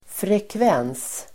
Uttal: [frekv'en:s]
frekvens.mp3